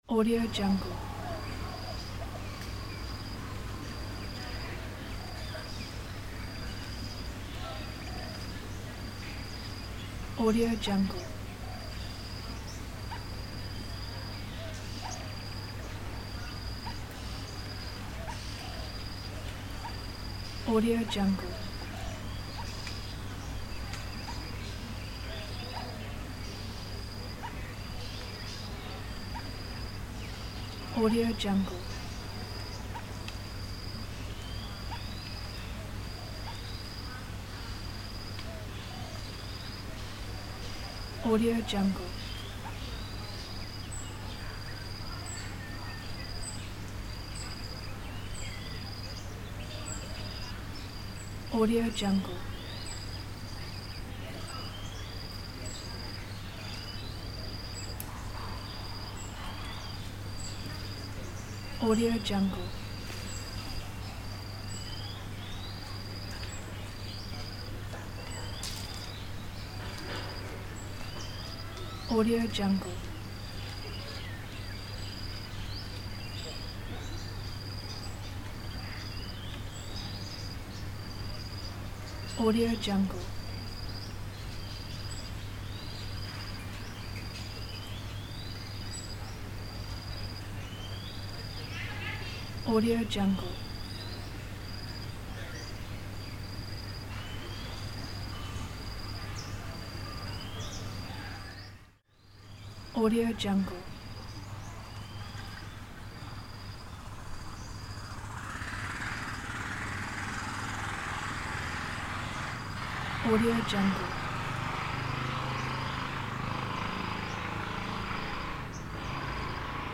دانلود افکت صدای محیط پارک در روز
دانلود افکت صوتی شهری
• ایجاد جو شاداب و پر انرژی: صدای پرندگان، خنده کودکان، وزش نسیم ملایم و سایر صداهای محیطی یک پارک، حس شادی و نشاط را به بیننده منتقل می‌کند و می‌تواند برای ایجاد جوهای شاد، خانوادگی یا ماجراجویانه در ویدیوهای شما استفاده شود.
• کیفیت بالا: این فایل صوتی با کیفیت بالا ضبط شده است تا بهترین تجربه صوتی را برای شما فراهم کند.
16-Bit Stereo, 44.1 kHz